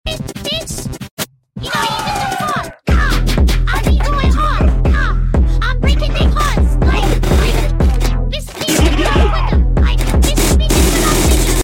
Roblox Guests Fighting sound effects free download